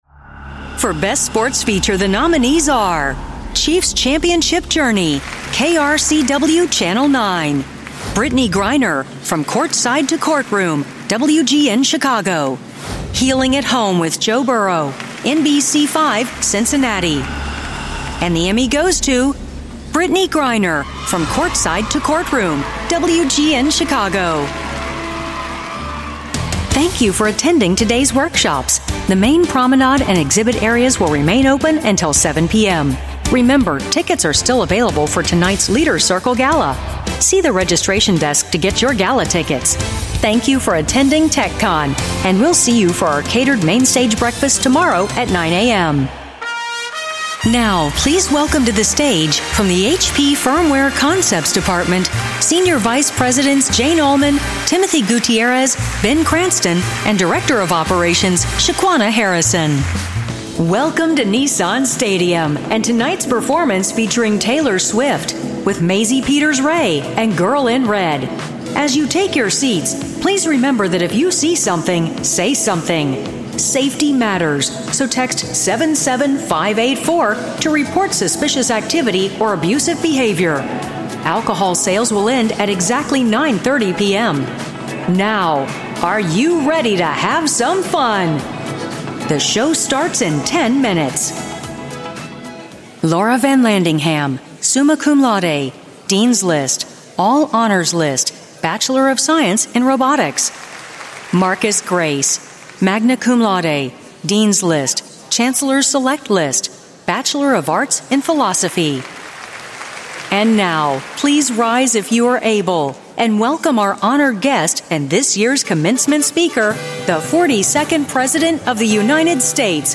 delivering a warm, articulate American English voice ideal for corporate narration, e-learning, political spots, and commercials
Announcements
WhisperRoom Isolation Booth, Roswell Pro Audio RA-VO mic, Sound Devices USBPre2 interface, Mac computers, Source Connect